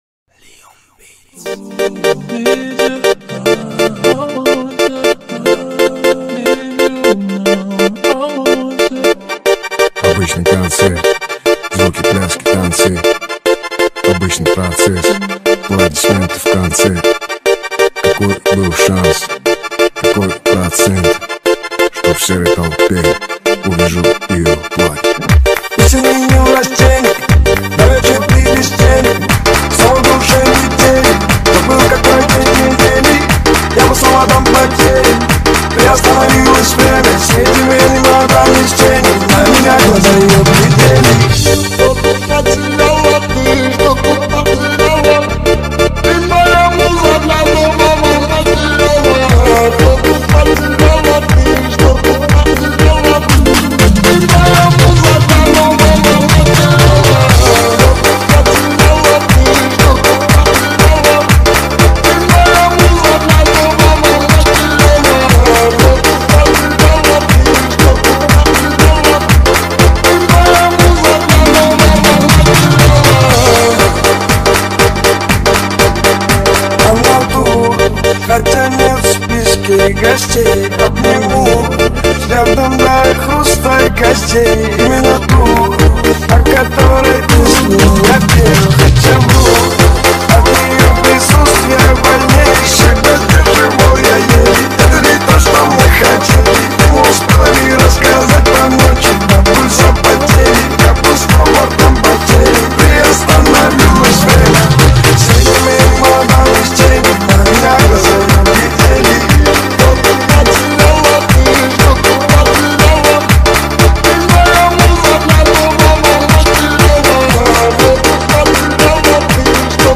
Лезгинка Ремикс